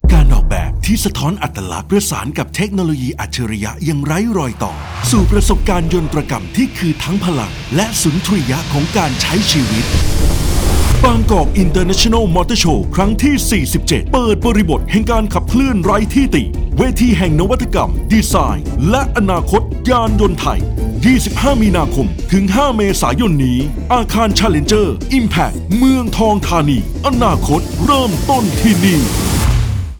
Radio Spot Motorshow47 30 วิ
spot-radio-30-วิ-Mts47.wav